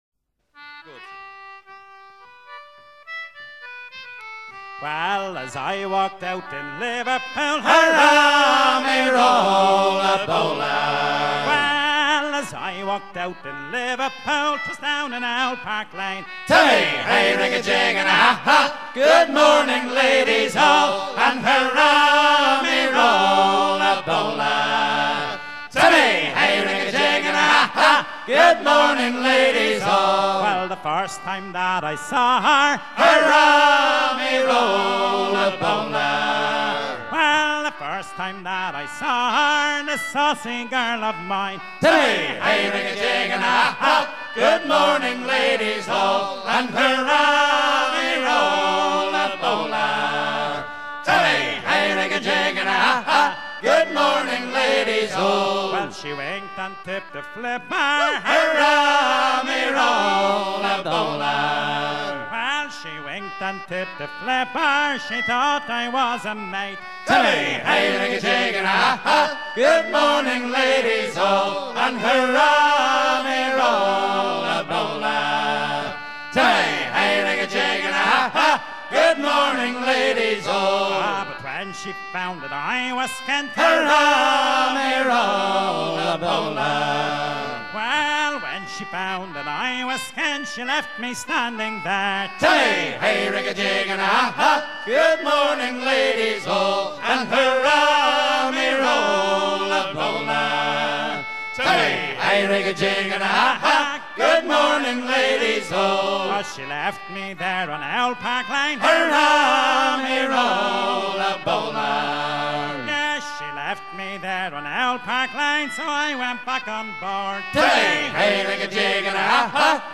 Il était chanté sur des voiliers antillais
à virer au cabestan
Pièce musicale éditée